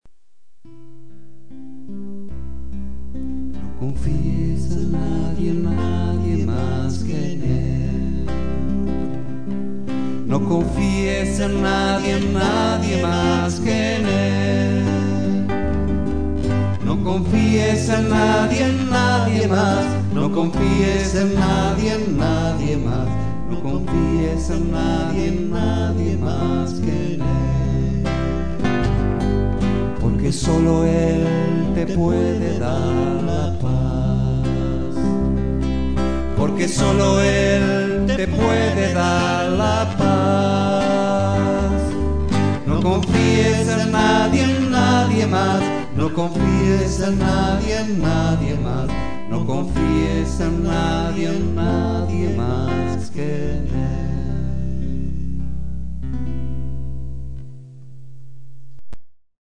voz y guitarra
bajo